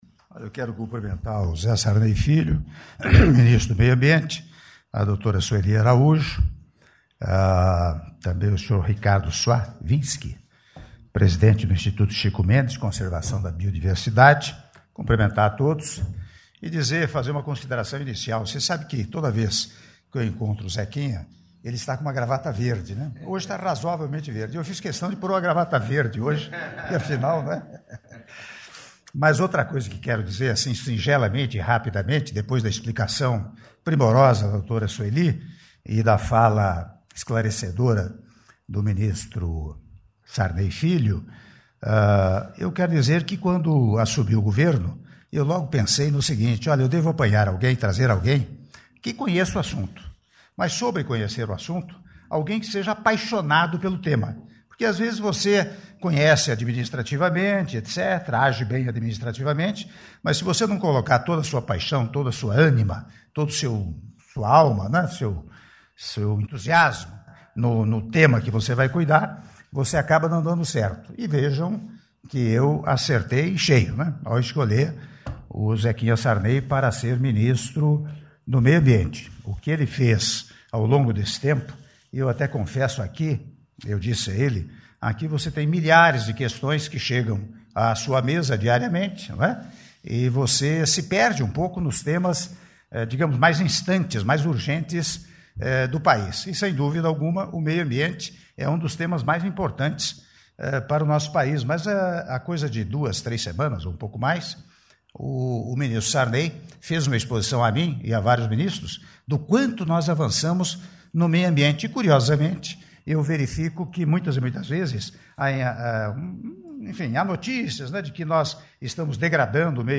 Áudio do discurso do Presidente da República, Michel Temer, durante Ato de Lançamento do Processo de Conversão de Multas Ambientais em Prol das Bacias dos Rios São Francisco e Parnaíba - (04min26s) - Brasília/DF